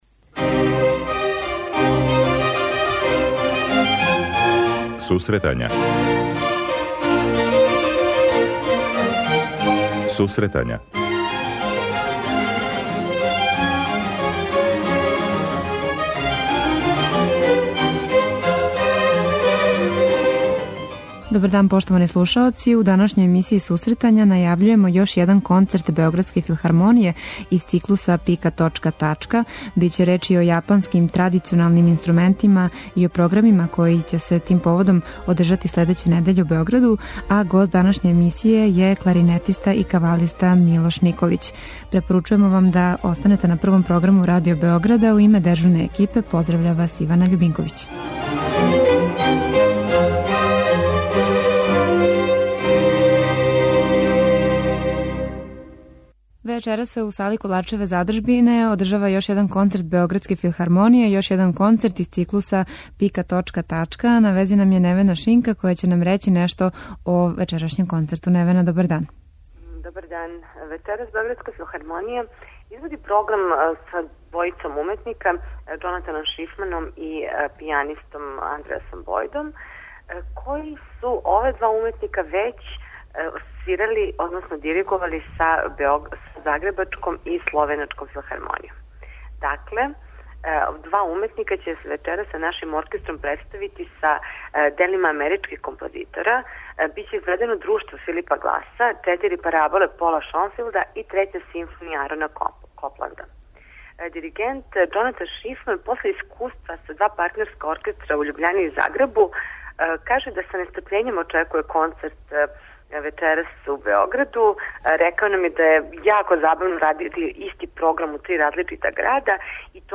преузми : 26.36 MB Сусретања Autor: Музичка редакција Емисија за оне који воле уметничку музику.